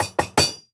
Minecraft Version Minecraft Version latest Latest Release | Latest Snapshot latest / assets / minecraft / sounds / block / smithing_table / smithing_table3.ogg Compare With Compare With Latest Release | Latest Snapshot
smithing_table3.ogg